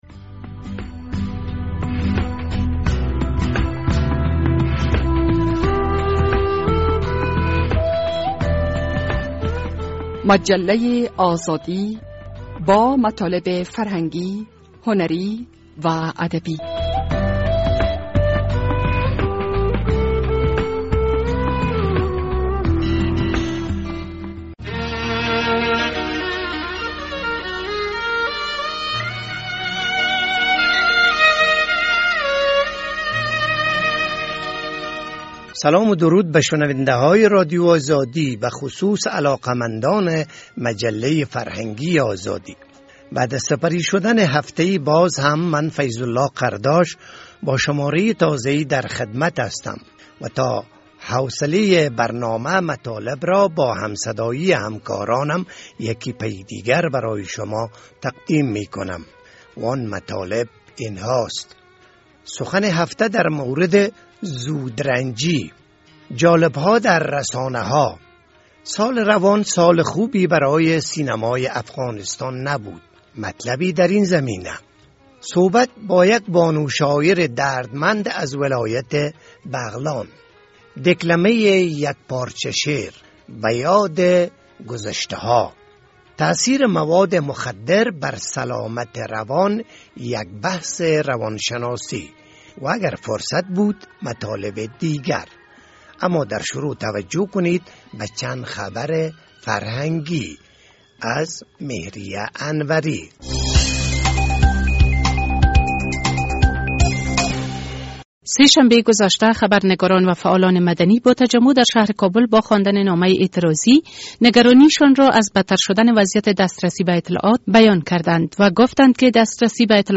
درین برنامه مجله آزادی این مطالب را داریم: سخن هفته در مورد زود رنجی، جالب‌ها در رسانه‌ها، دکلمه یک پارچه شعر، سال روان سال خوبی برای سینمای افغانستان نبود